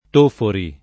[ t 1 fori ]